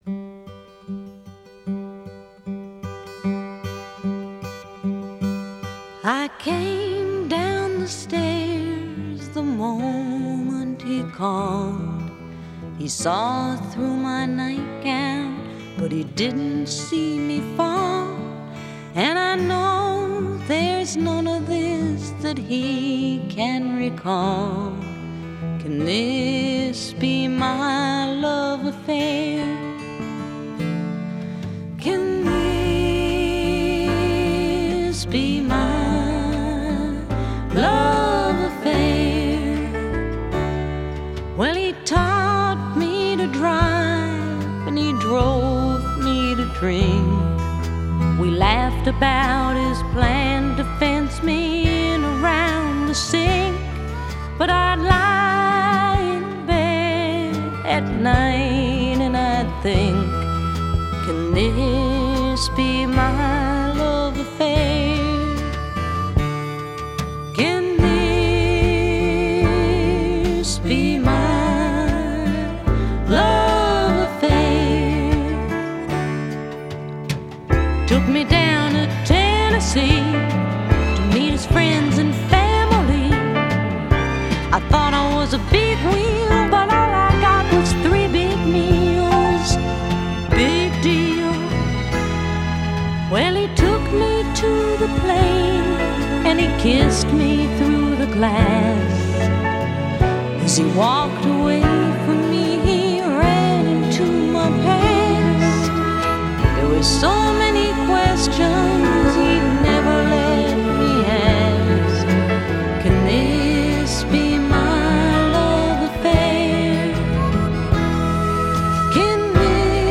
Genre: Pop, Folk, Rock